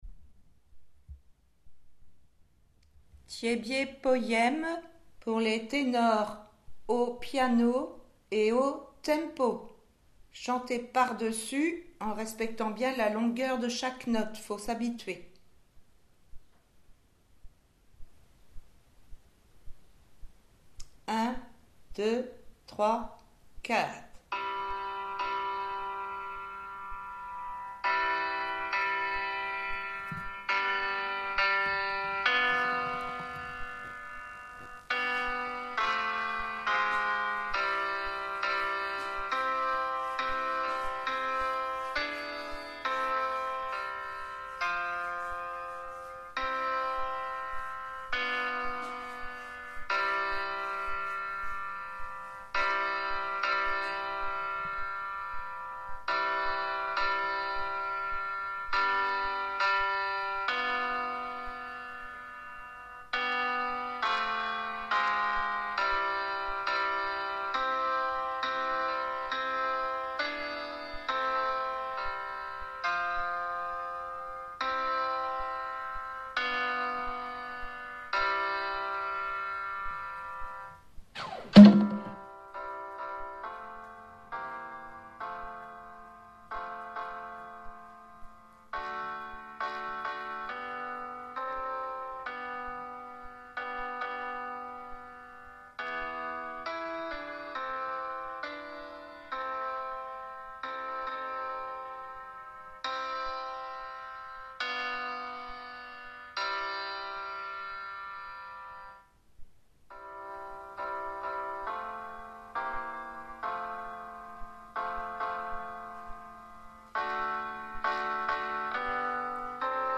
Tiébié piano Ténor